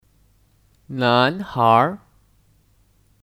男孩儿 Nánháir (Kata benda): Anak laki-laki